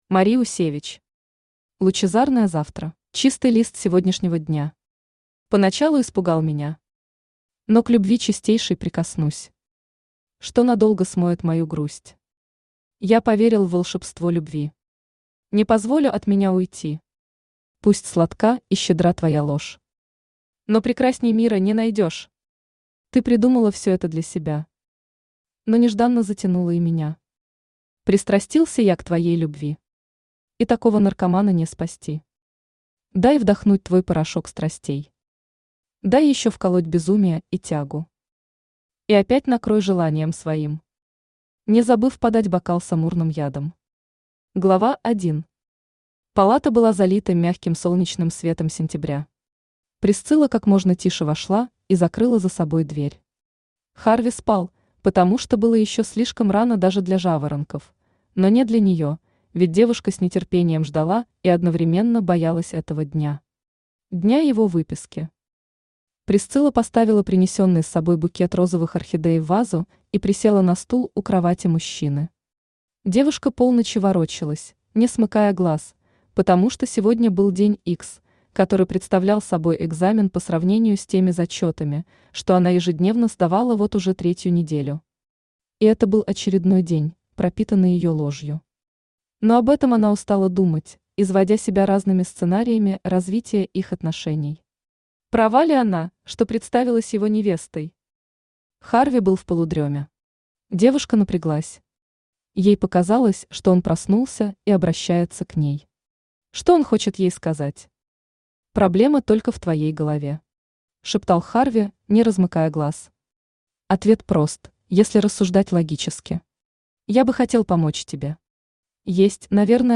Аудиокнига Лучезарное завтра | Библиотека аудиокниг
Aудиокнига Лучезарное завтра Автор Мари Усевич Читает аудиокнигу Авточтец ЛитРес.